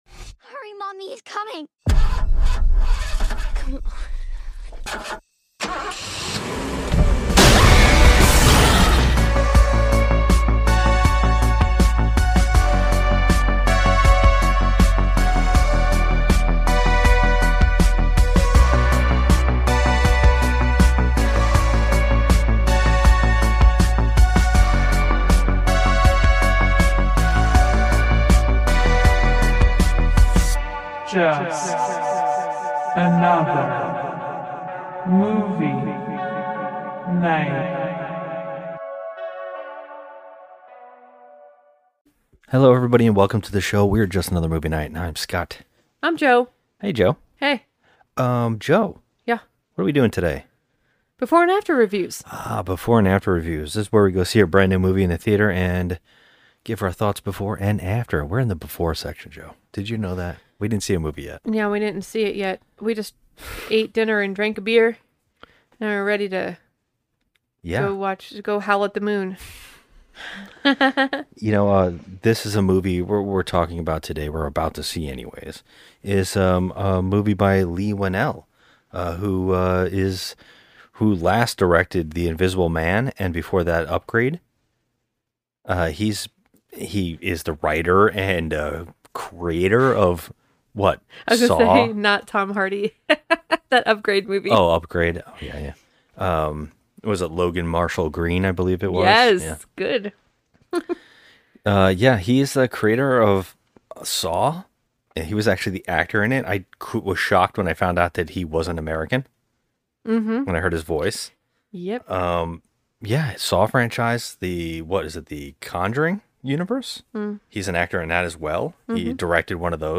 Finally we decided to get off our lazy Asses and start recording our nightly rants, reviews and conversations on all things entertainment (but mainly horror movies).